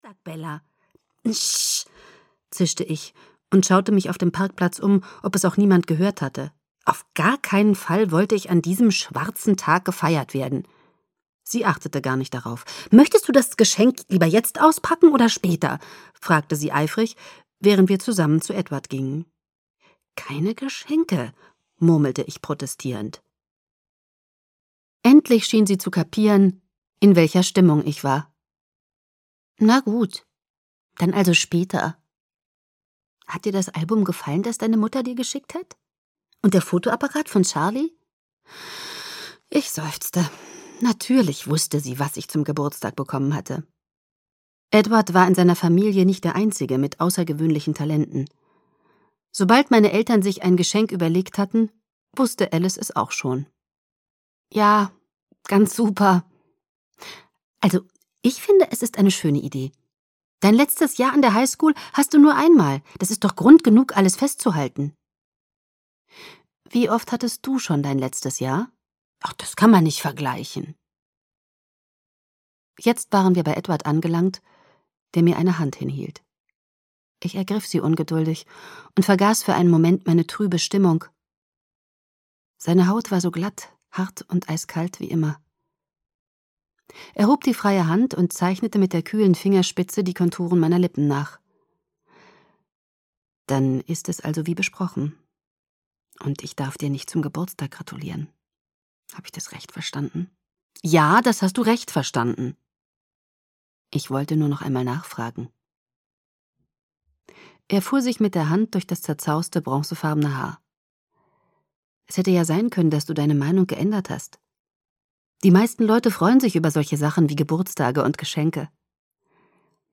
Bella und Edward 2: Biss zur Mittagsstunde - Stephenie Meyer - Hörbuch